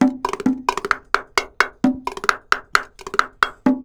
BONG 01.AI.wav